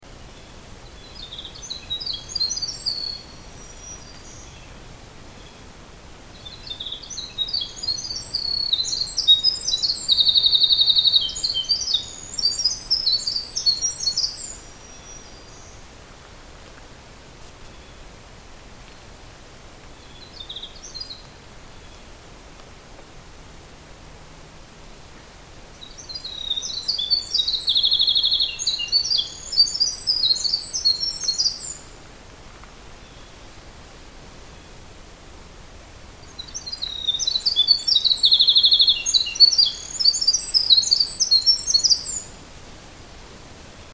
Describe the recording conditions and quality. And here is a bit 'o Winter Wren song, recorded near my house a few days ago.